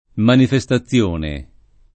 [ manife S ta ZZL1 ne ]